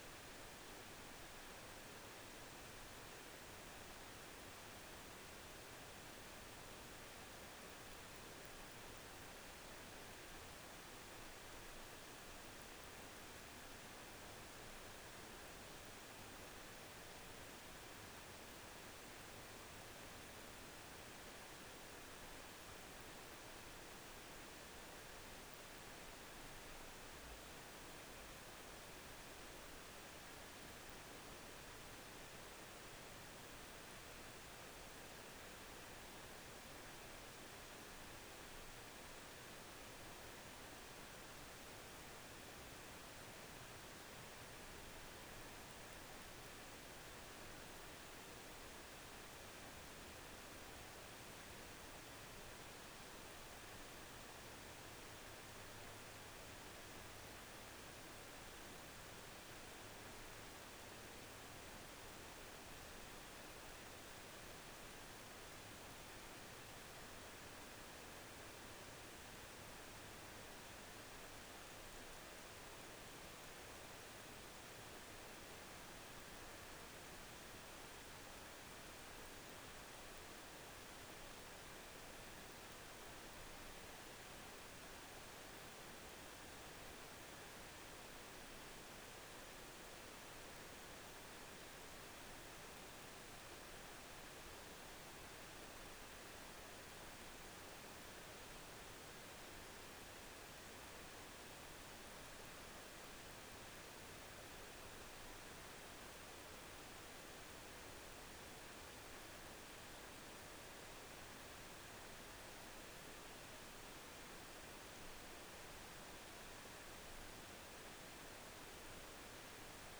biophony